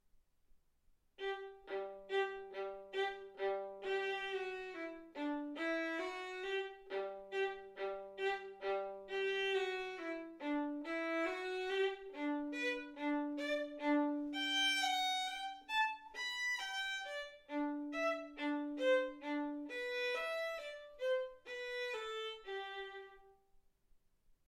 Hegedű etűdök
Classical music